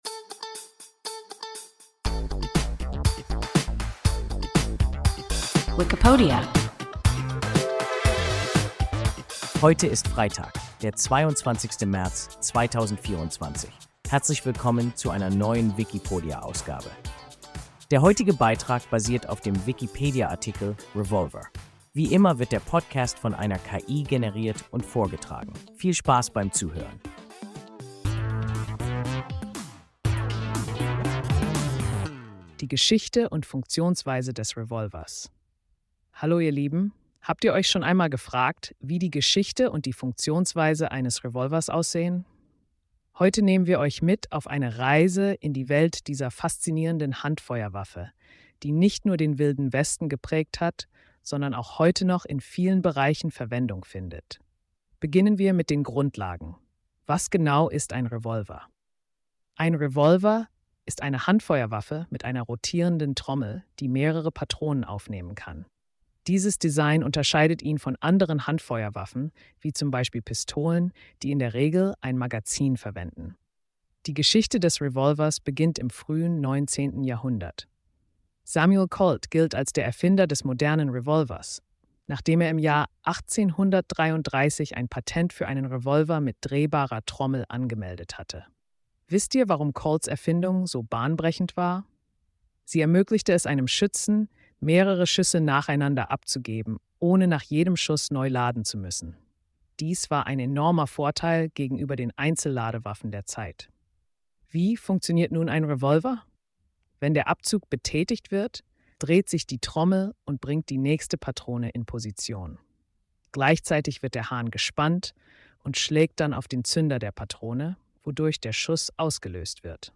Revolver – WIKIPODIA – ein KI Podcast